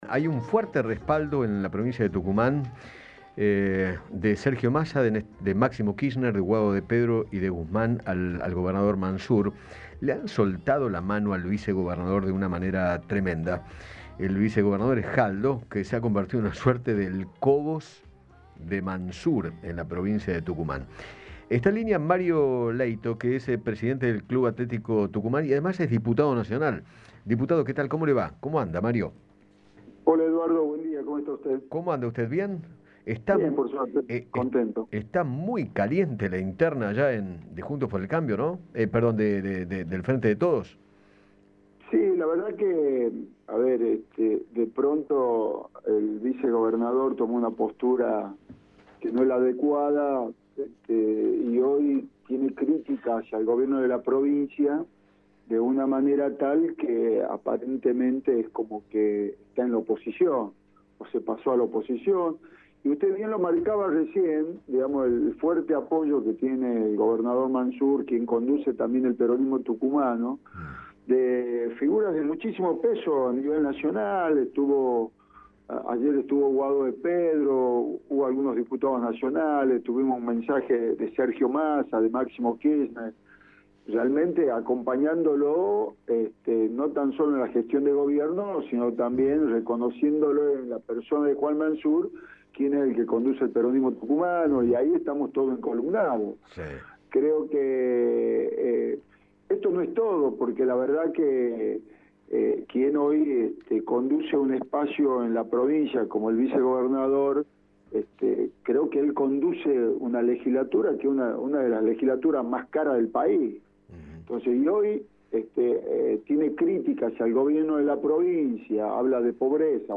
conversó con Eduardo Feinmann sobre la interna del Frente de Todos en aquella provincia y manifestó que “de pronto el vicegobernador